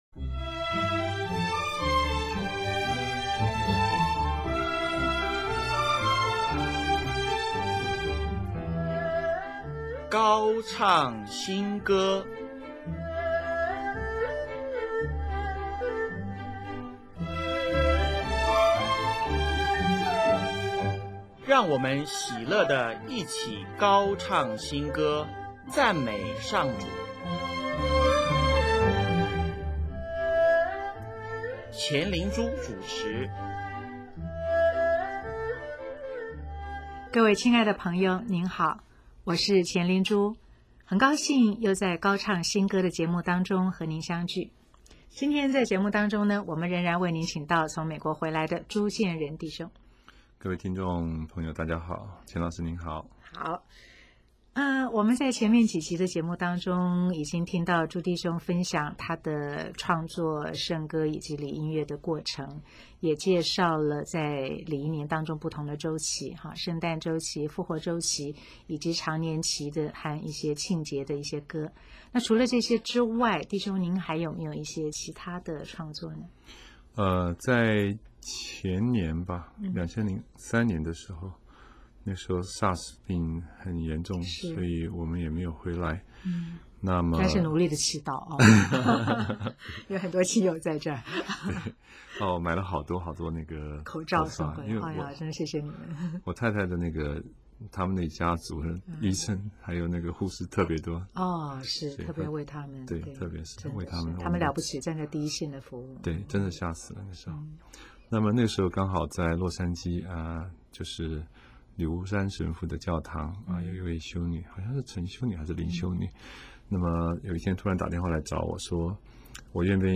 本集现场演唱“慈悲串经歌”、“请求玛丽亚”、“请不要松手”。